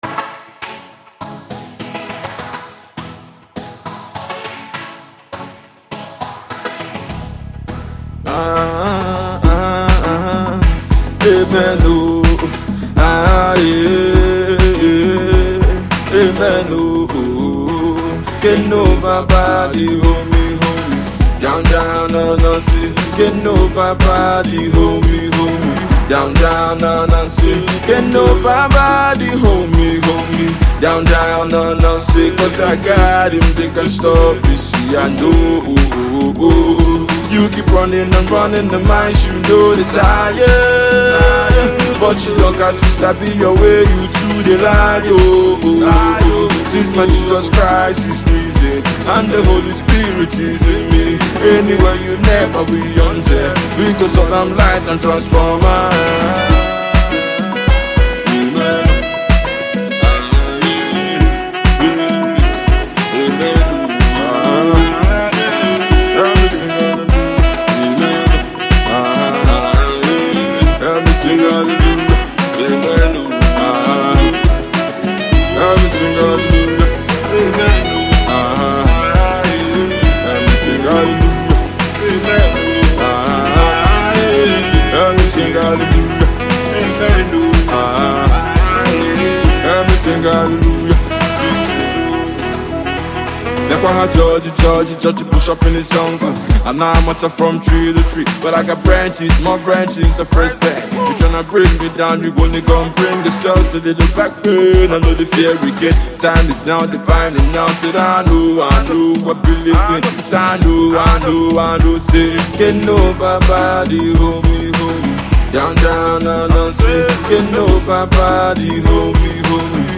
Alternative Pop
alternative rap/pop
quite enjoyable and groovy